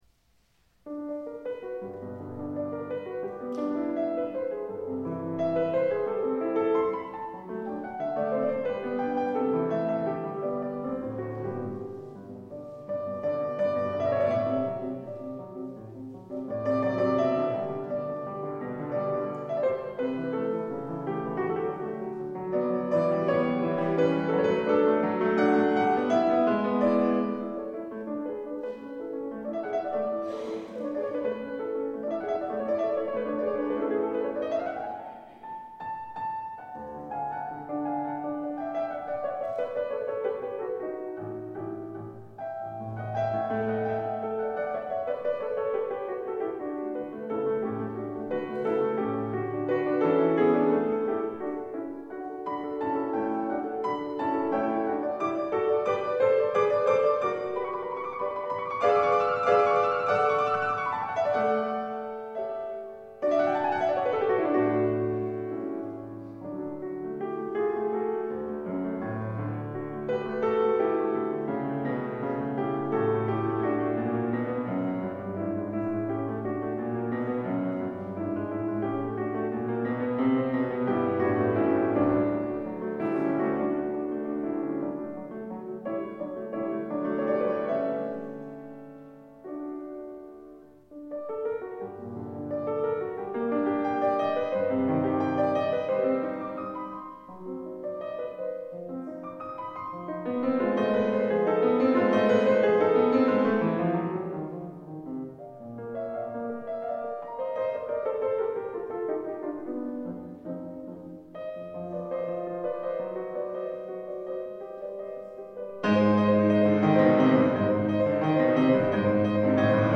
Absolventský koncert
klavír